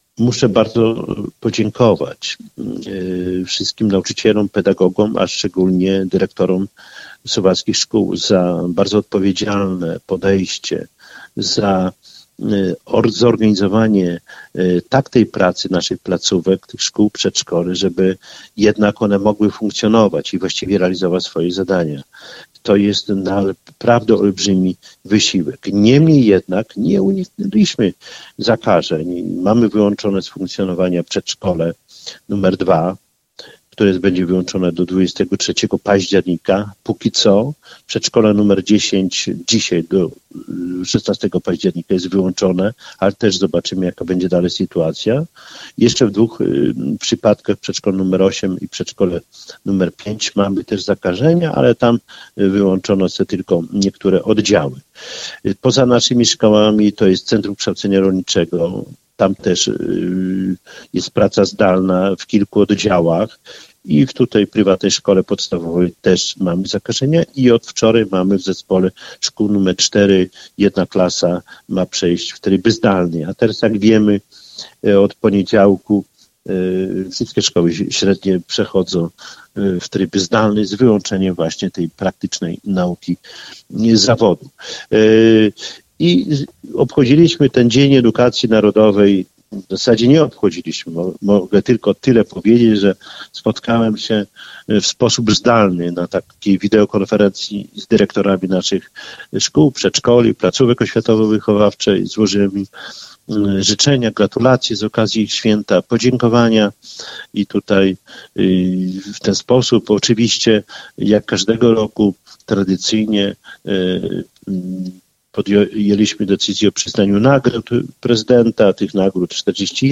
O pracy szkół oraz obchodzonym niedawno Dniu Edukacji Narodowej mówił dziś na antenie Radia 5 Czesław Renkiewicz, Prezydent Suwałk.